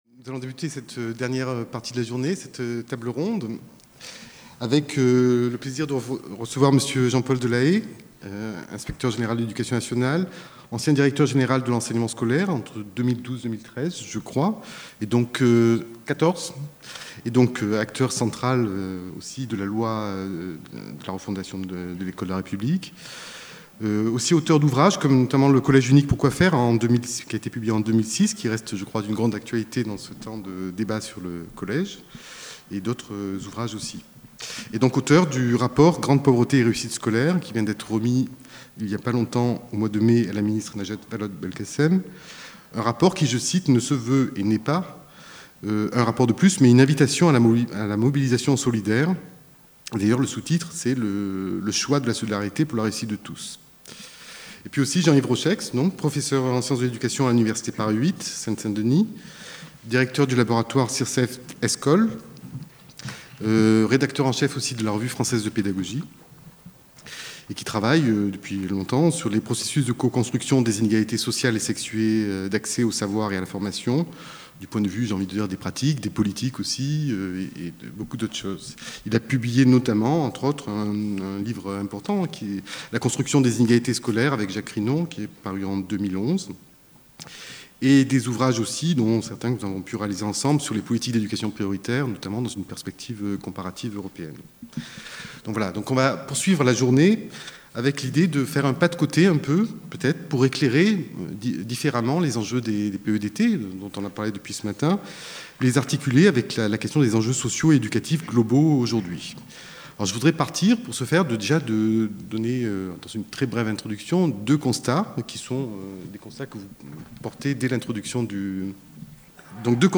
Le programme de la journée La journée a vu alterner des séances plénières sur les problématiques liées aux Projets Educatifs de Territoires (PEdT) et des temps de retour d’expériences locales en ateliers. Le matin a débuté par la présentation de quelques observations réalisées et synthétisées par l’Observatoire PoLoc, autour d'une table-ronde qui a réunit bon nombre de partenaires.